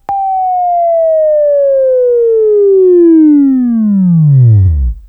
Sweeping training / Wavefile / Buzz